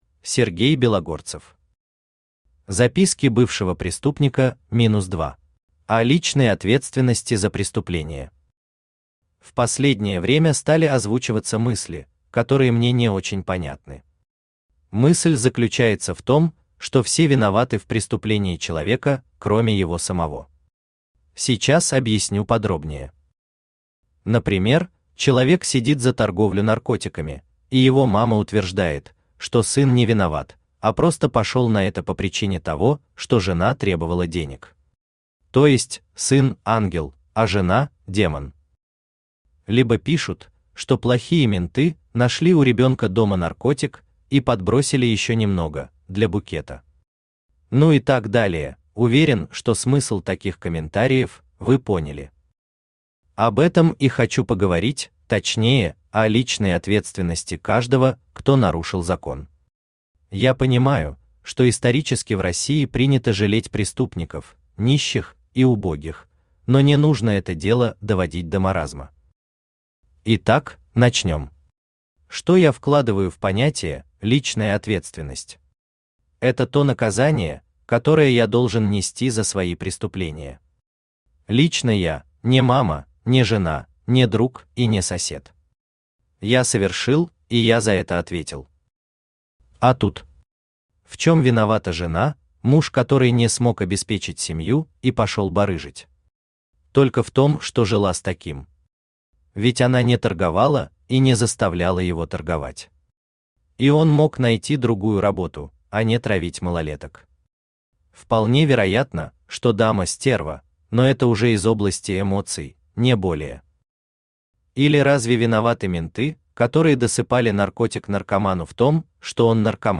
Aудиокнига Записки бывшего преступника -2 Автор Сергей Белогорцев Читает аудиокнигу Авточтец ЛитРес.